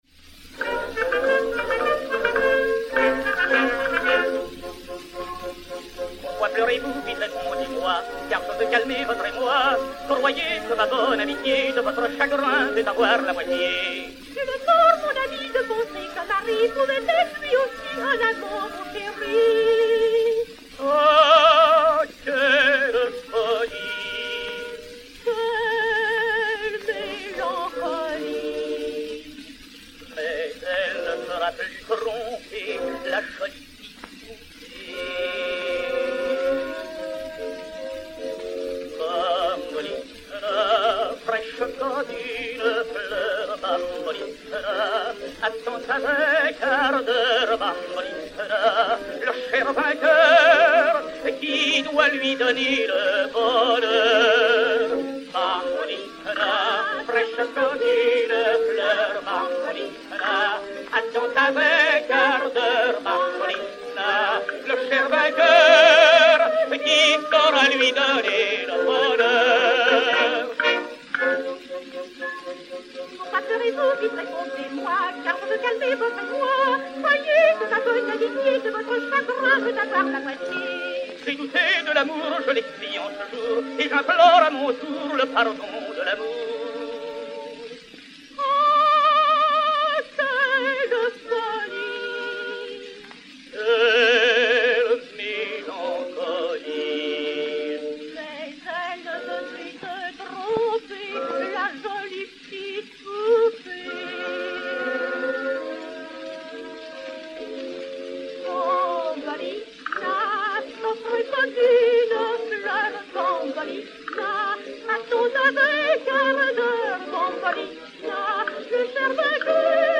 soprano français
Duo